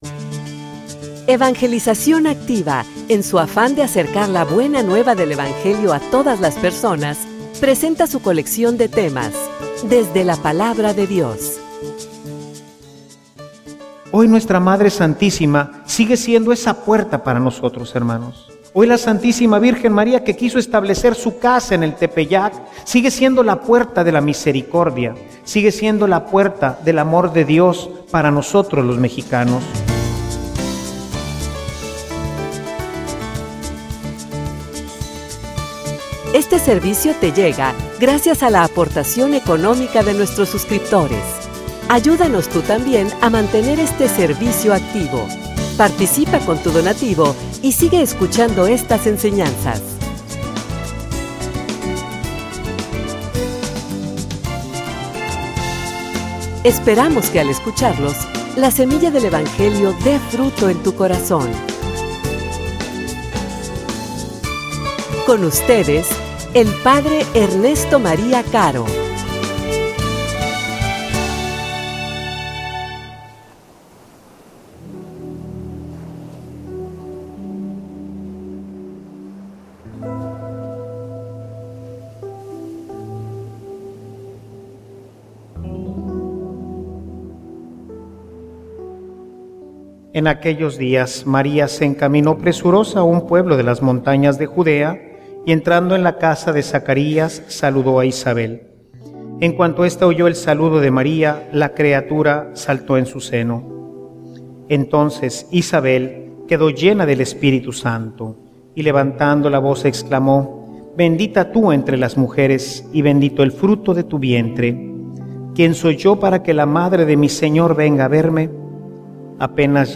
homilia_La_Tecoatlasupe.mp3